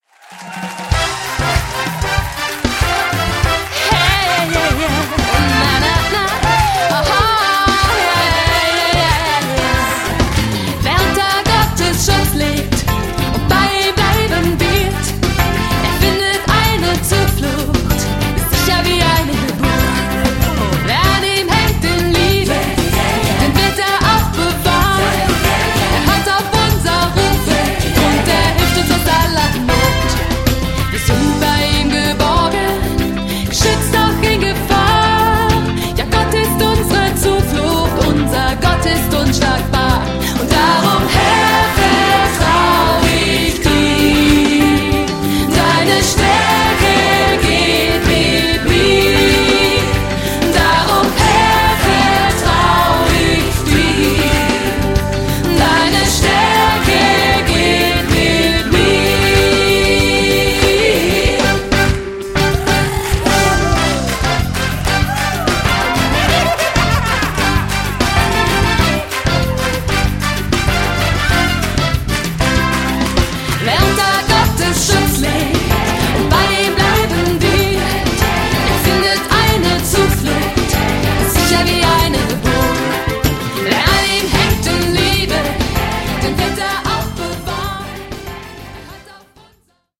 • Sachgebiet: deutscher Lobpreis